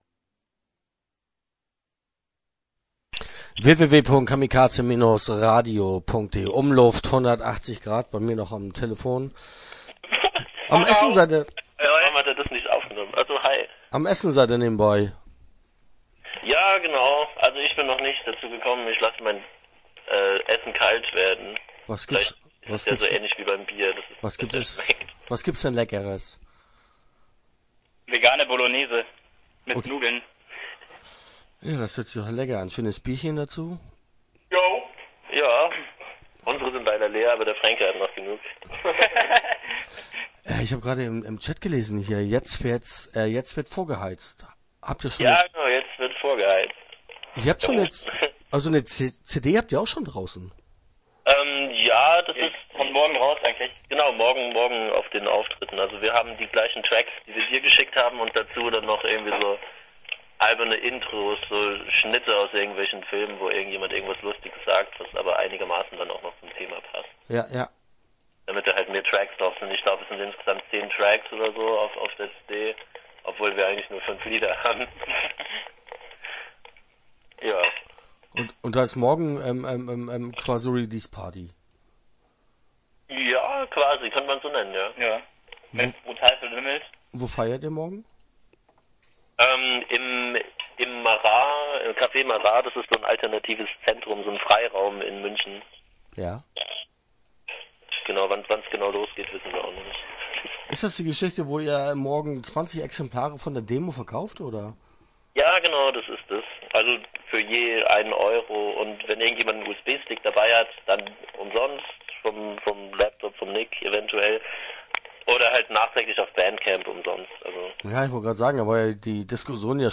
Interview Teil 1 (8:44)